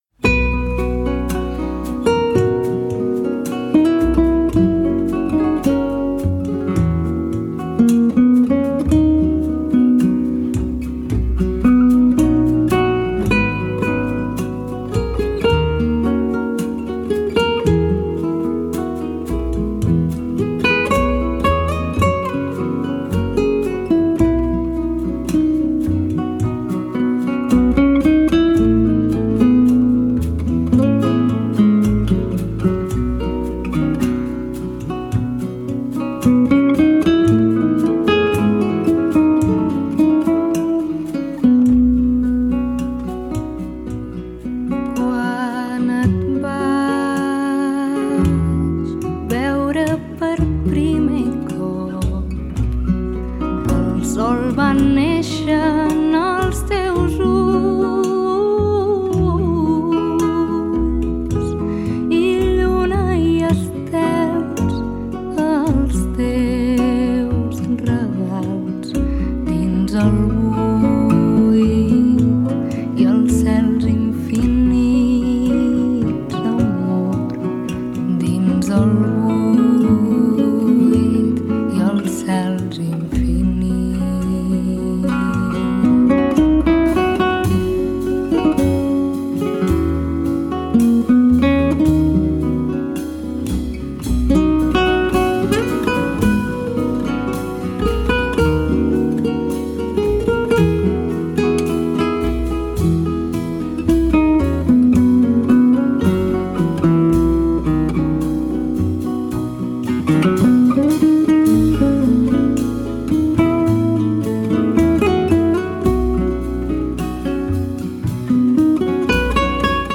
南米フォルクローレや英米トラッドフォークとは一味違う、カタルーニャの情感が詰め込まれた傑作！
心地良い郷愁感に酔えますよ！